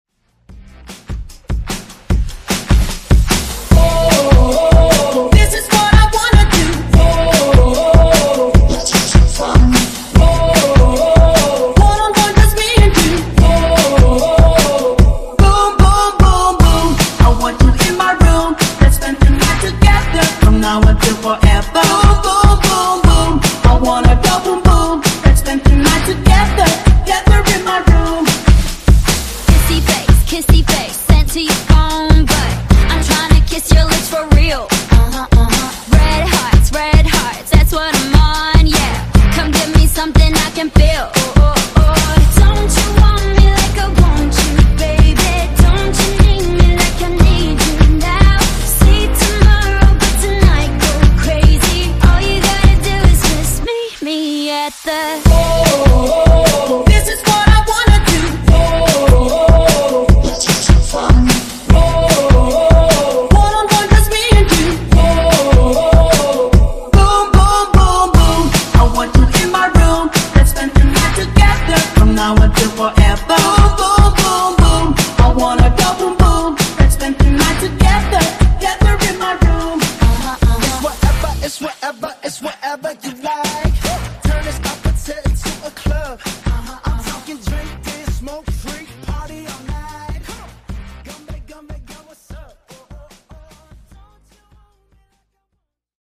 Genres: R & B , RE-DRUM
Dirty BPM: 102 Time